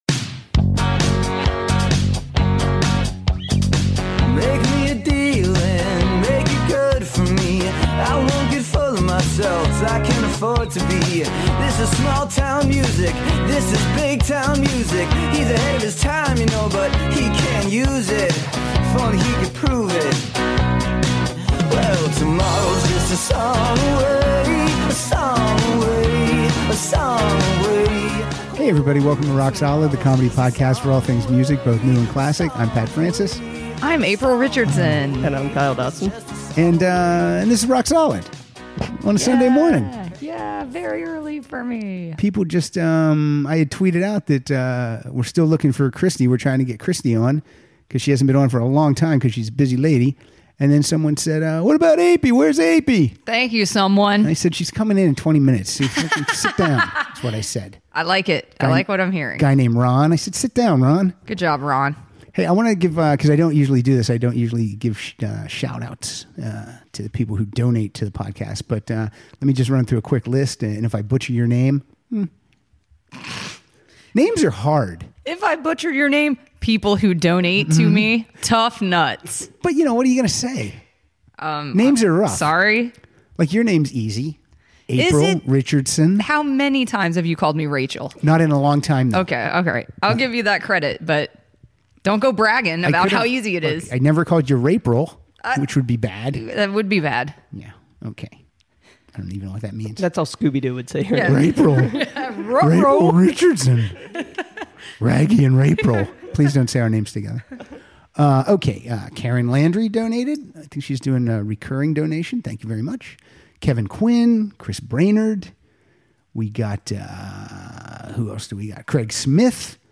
play pairs of songs that they think sound very familiar.